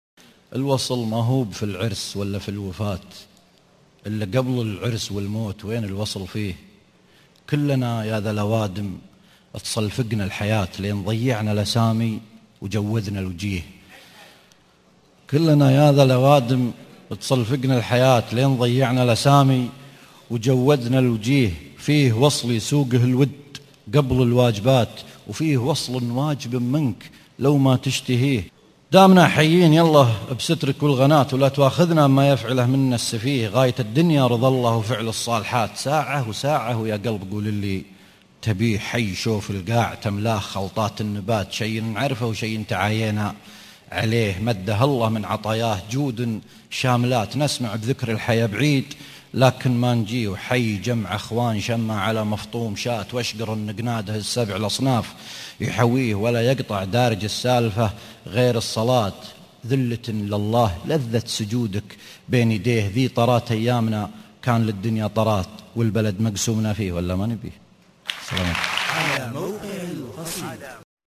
الوصل ( امسية سان دييغو 2013 )   18 ديسمبر 2013